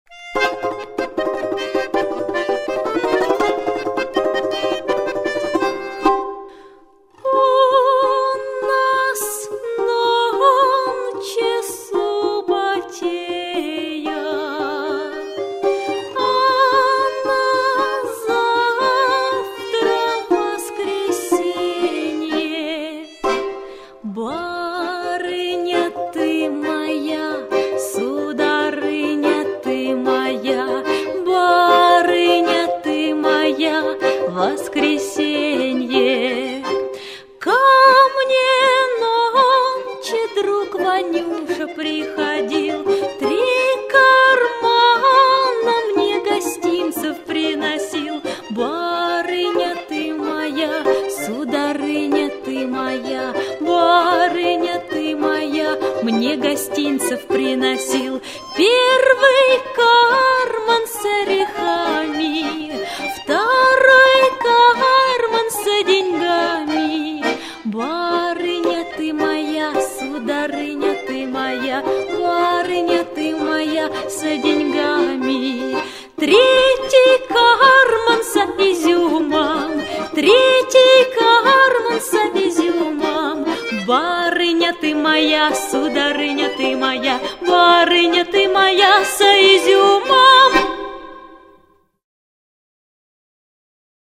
Музыка народная.